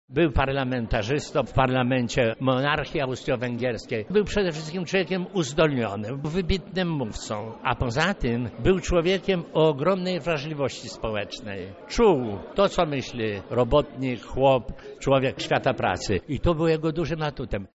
historyk oraz politolog.